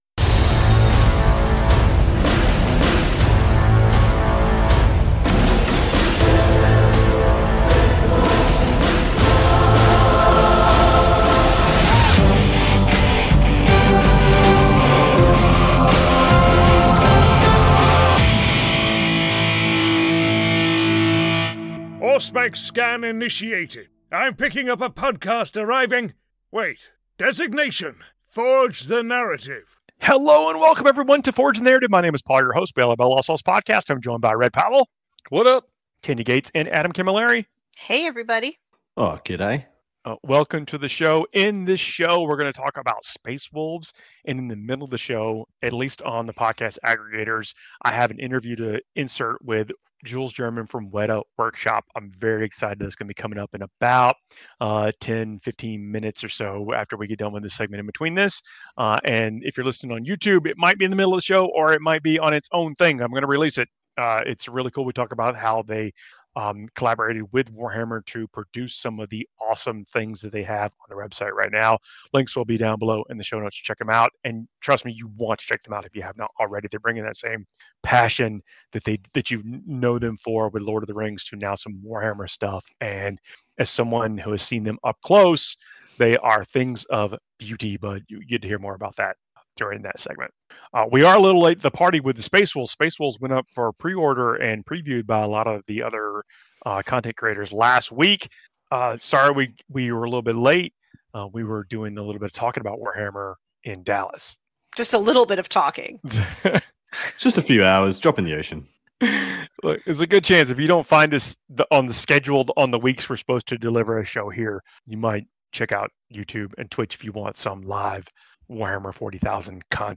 Forge the Narrative is mostly a Warhammer 40k Podcast.